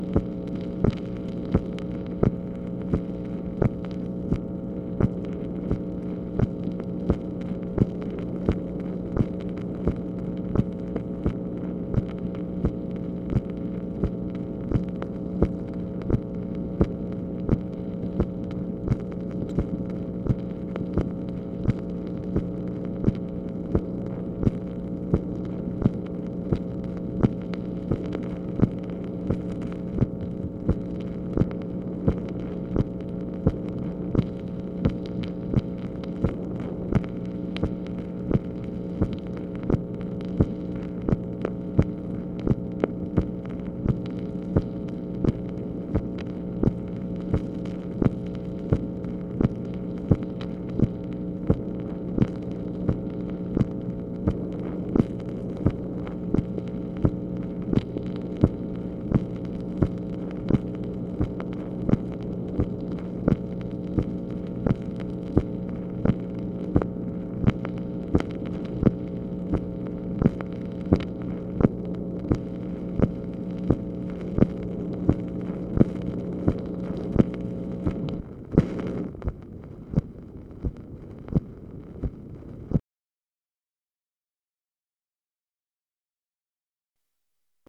MACHINE NOISE, February 15, 1965
Secret White House Tapes | Lyndon B. Johnson Presidency